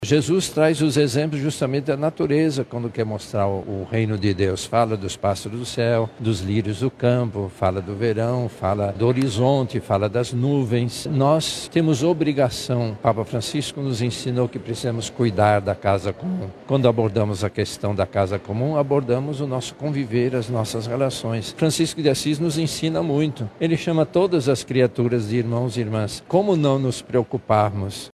Em uma coletiva de imprensa realizada na manhã desta terça-feira, 26 de agosto, no auditório da Cúria Metropolitana, a Arquidiocese de Manaus apresentou a 31° edição do Grito dos Excluídos e Excluídas.
SONORA-2-CARDEAL.mp3